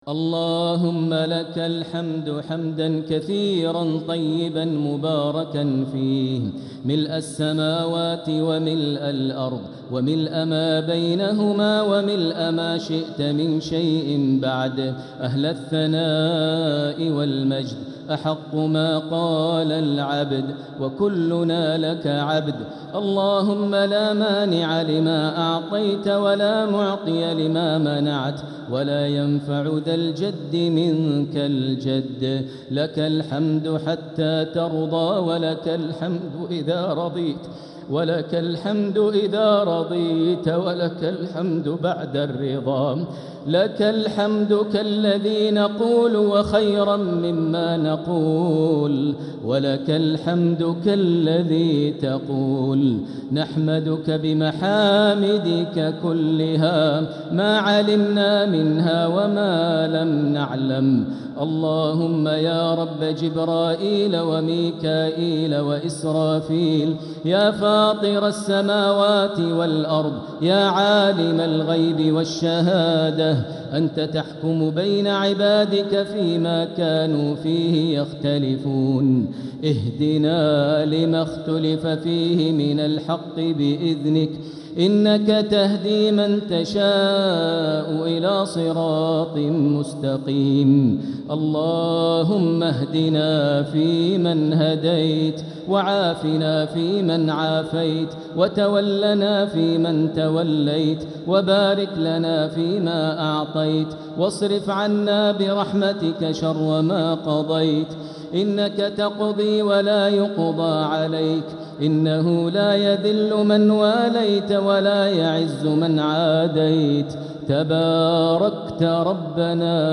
دعاء القنوت ليلة 8 رمضان 1446هـ | Dua 8th night Ramadan 1446H > تراويح الحرم المكي عام 1446 🕋 > التراويح - تلاوات الحرمين
Al-MuaiqlyDua.mp3